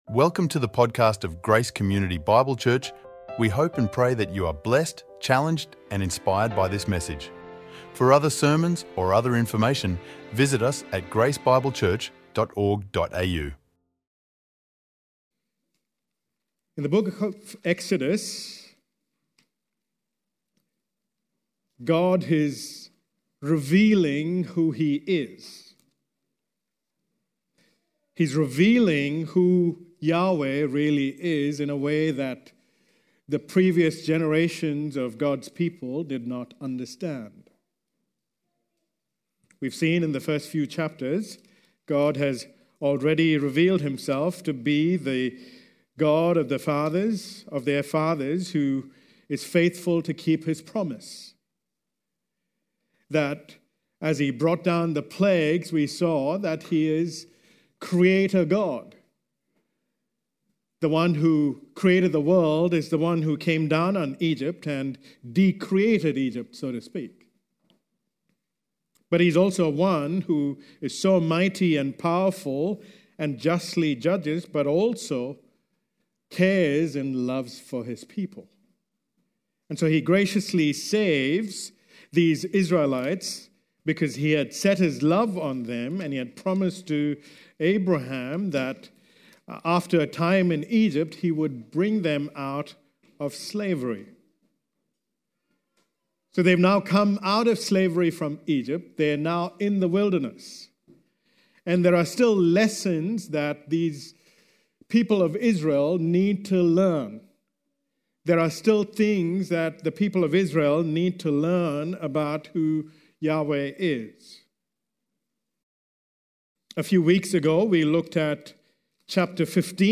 recorded live at Grace Community Bible Church